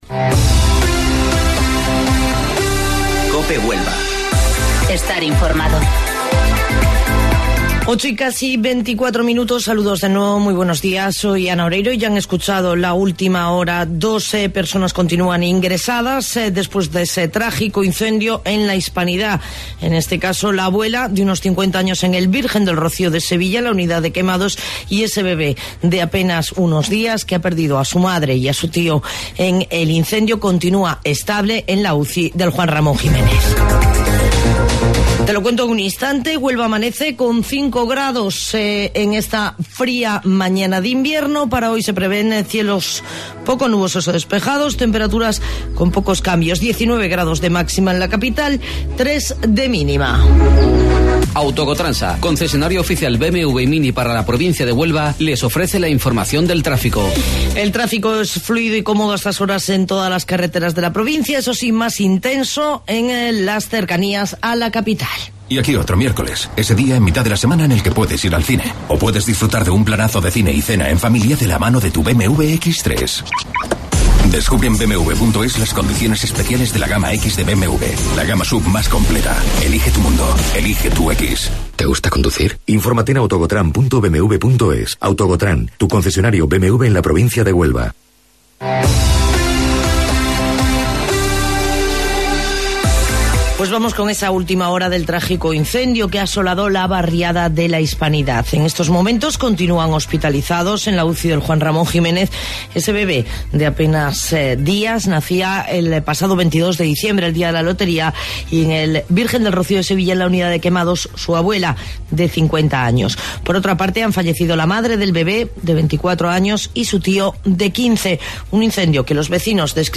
AUDIO: Informativo Local 08:25 de 8 de Enero